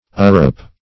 upprop - definition of upprop - synonyms, pronunciation, spelling from Free Dictionary Search Result for " upprop" : The Collaborative International Dictionary of English v.0.48: Upprop \Up*prop"\, v. t. To prop up.